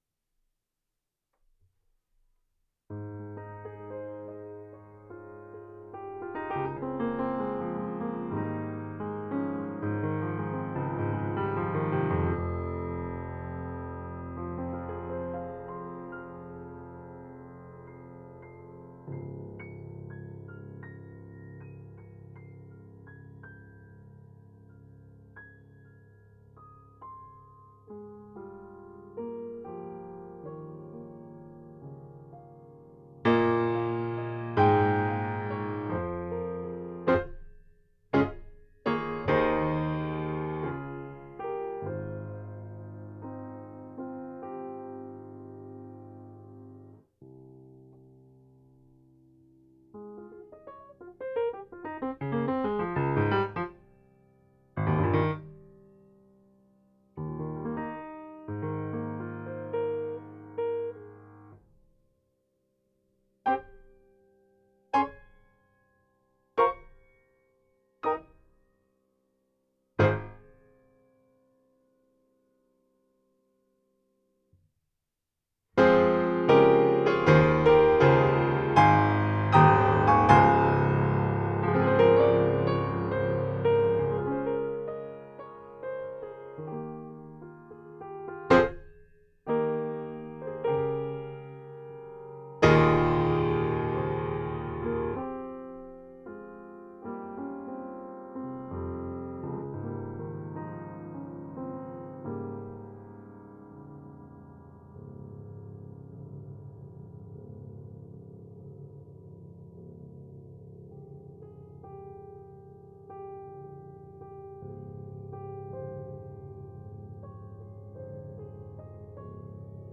Piano improvisations
Improv 1 (January 2017) (3:24) Improv 2 (mostly in the octatonic II scale) (January 2017) (4:32) Improv 3 (January 2017) (5:54) Mic test (testing loud, soft and string resonances) (January 2017) (2:39)
mic_test_h4n.m4a